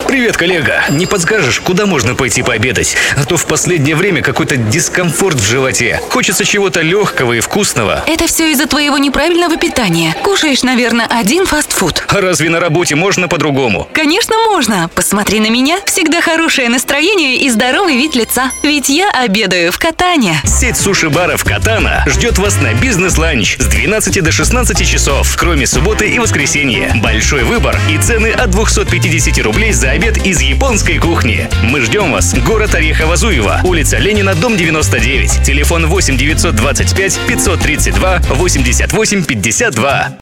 Игровой радиоролик бизнес-ланч "Катана" Категория: Аудио/видео монтаж